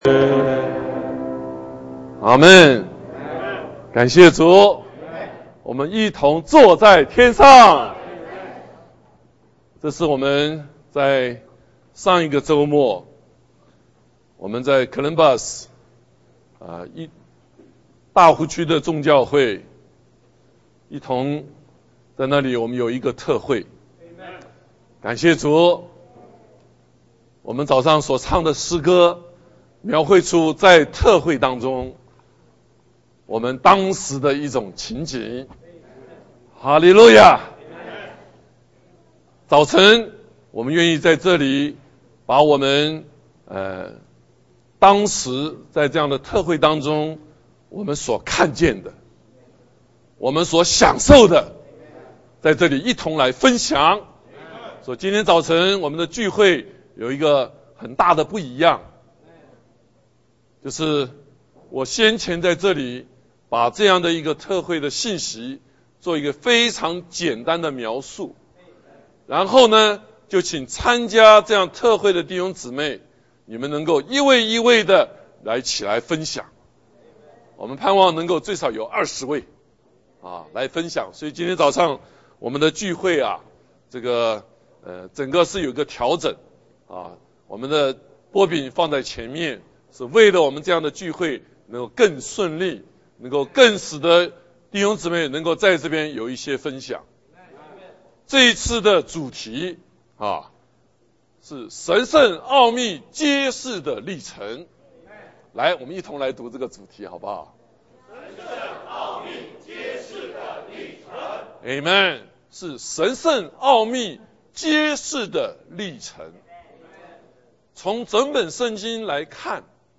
主題： 神圣奧秘揭示的歷程–國殤節特會見證分享 信息： （圣徒見證，77分鐘）， 下载mp3文档（右键点击下载） 詩歌： 特會詩歌–無限無價的你，奇妙，與主同坐天上 經節： 加拉太書 1:4, 16 哈里路亞！